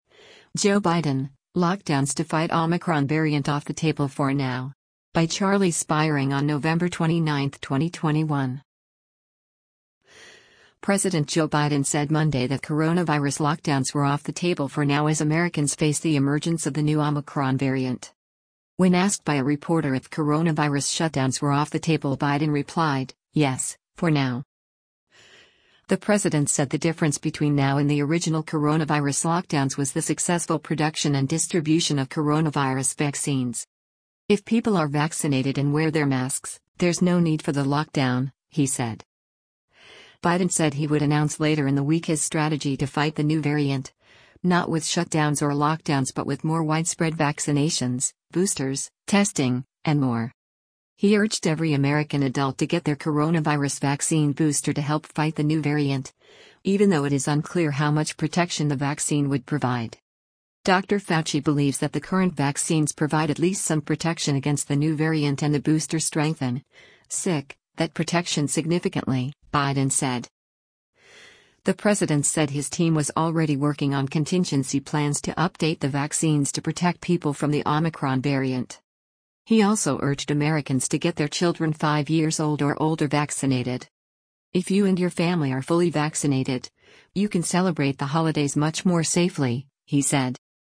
WASHINGTON, DC - NOVEMBER 29: U.S. President Joe Biden delivers remarks on the Omicron COV
When asked by a reporter if coronavirus shutdowns were “off the table” Biden replied, “Yes, for now.”